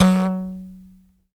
Index of /90_sSampleCDs/Roland LCDP11 Africa VOL-1/PLK_Buzz Kalimba/PLK_HiBz Kalimba